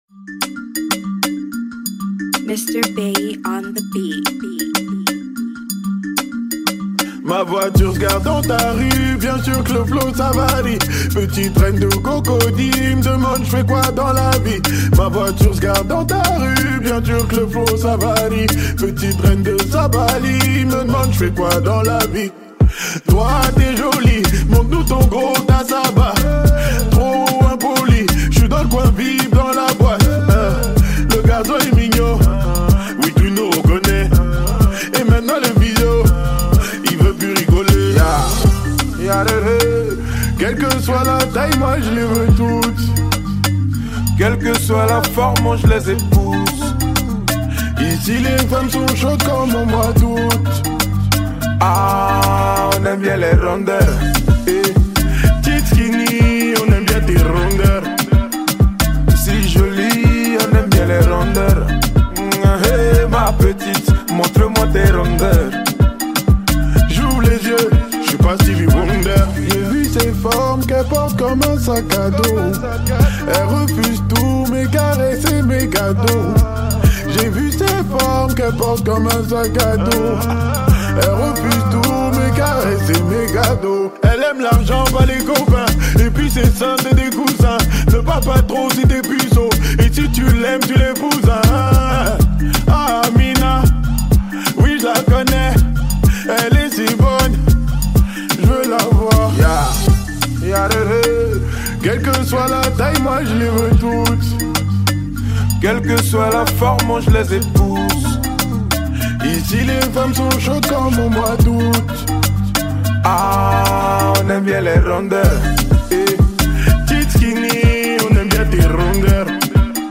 | Afro décalé